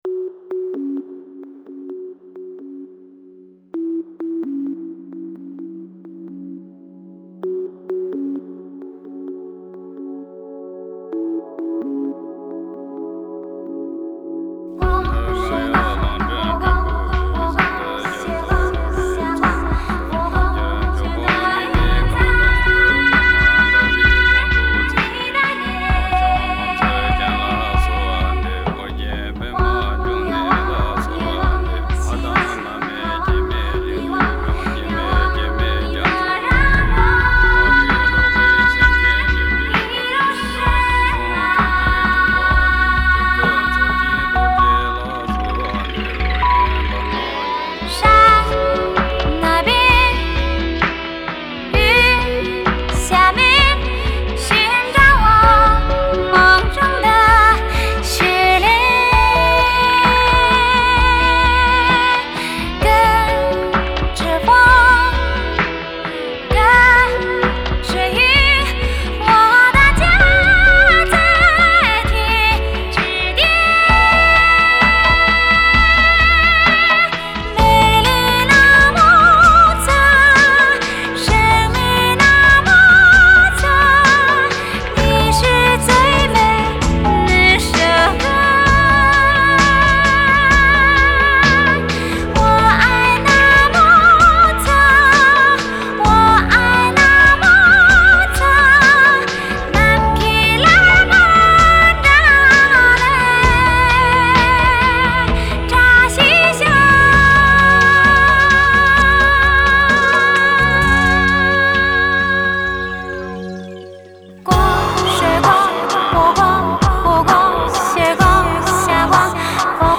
Жанр: Modern Traditional / Chinese pop / Tibetan folk